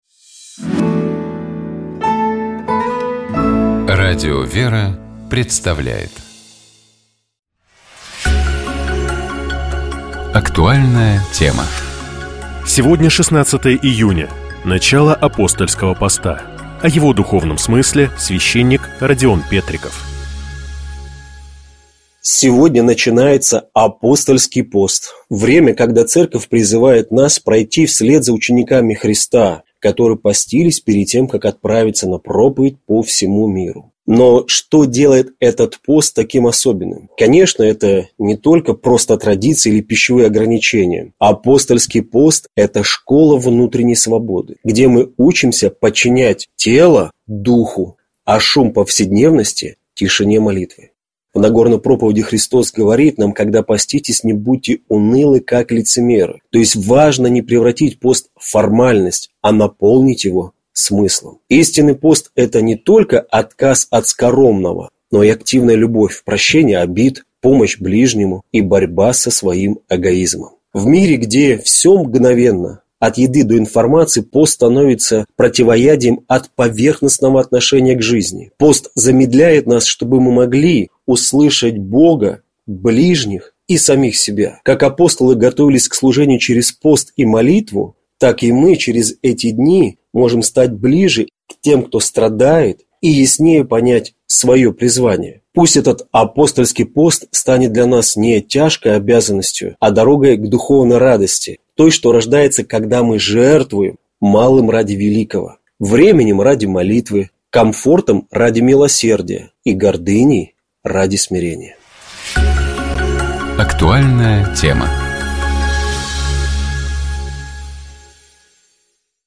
Давайте поразмышляем над текстом песнопения и послушаем его отдельными фрагментами в исполнении сестёр храма Табынской иконы Божией Матери Орской епархии.